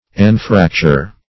Anfracture \An*frac"ture\